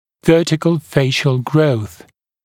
[‘vɜːtɪkl ‘feɪʃl grəuθ][‘вё:тикл ‘фэйшл гроус]вертикальный рост лица